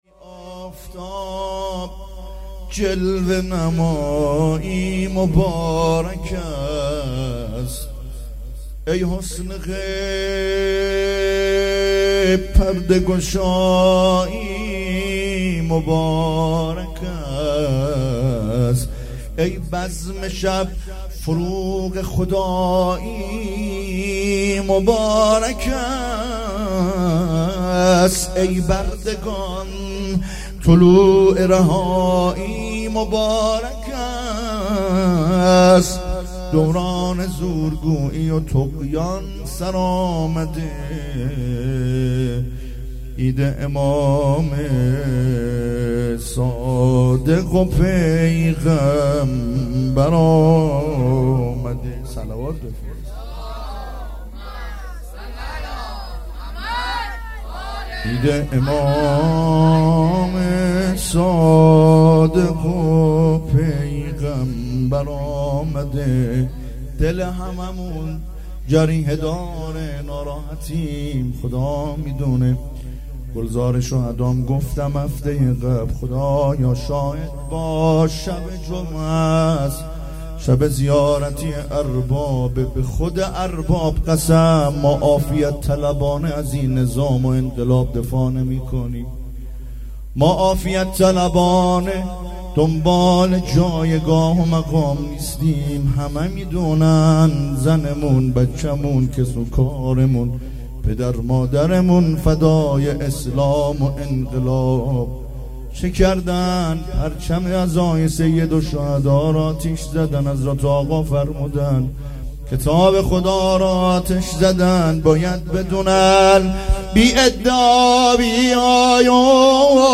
ولادت حضرت رسول(ص) و امام صادق (ع) ۱۴۰۱